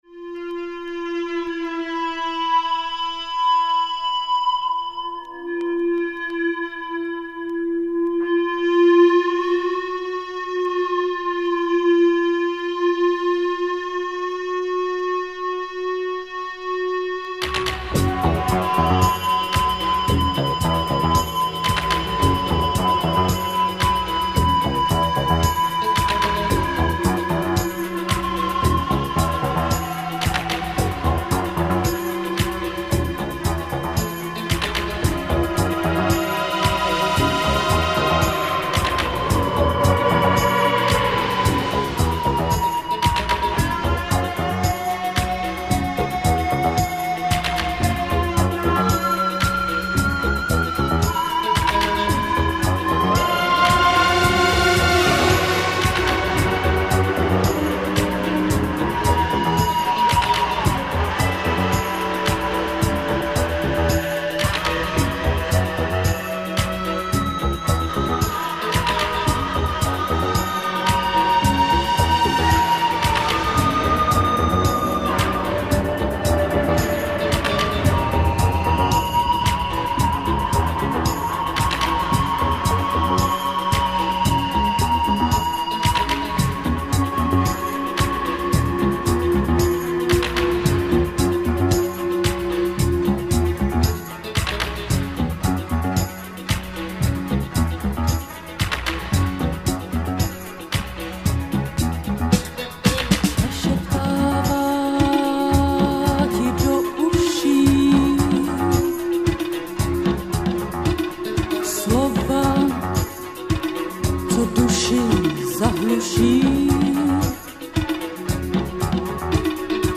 dark electro / wave
Trendy sound nowadays.